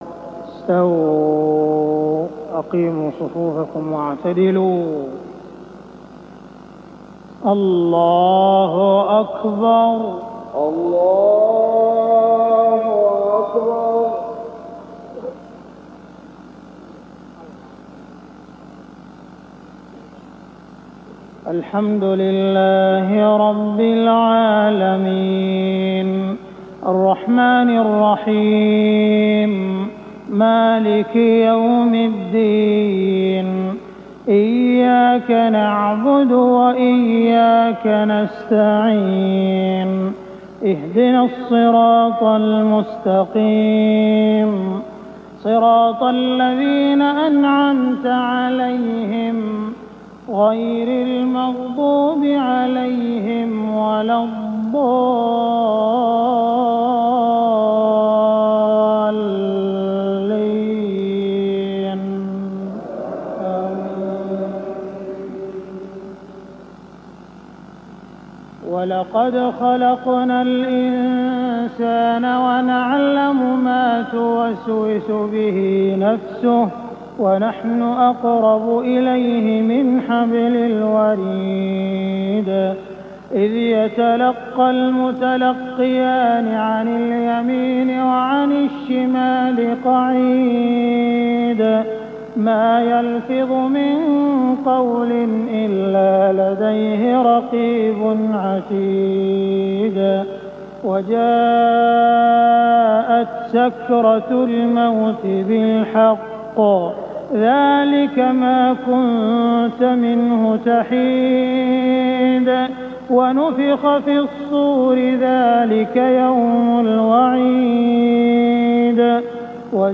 صلاة الفجر 2 رمضان 1419هـ خواتيم سورة ق 16-45 > 1419 🕋 > الفروض - تلاوات الحرمين